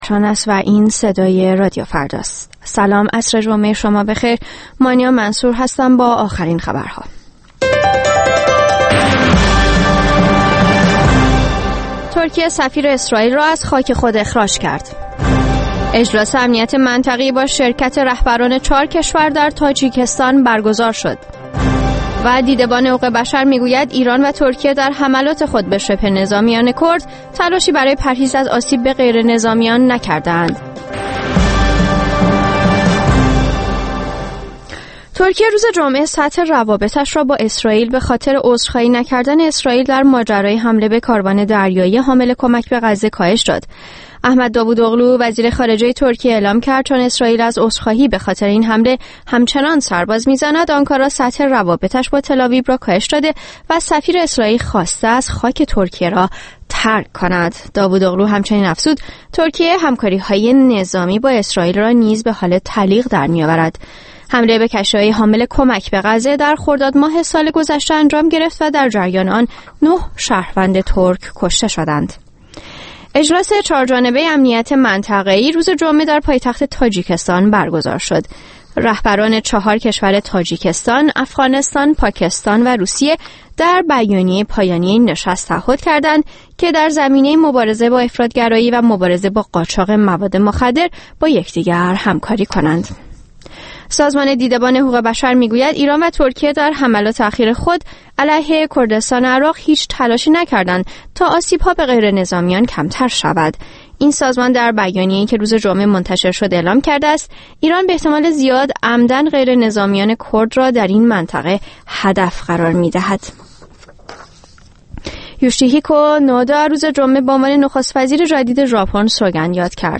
در کنار تماس‌های زنده شما، به مدت یک ساعت، از ارومیه بگوییم و بشنویم.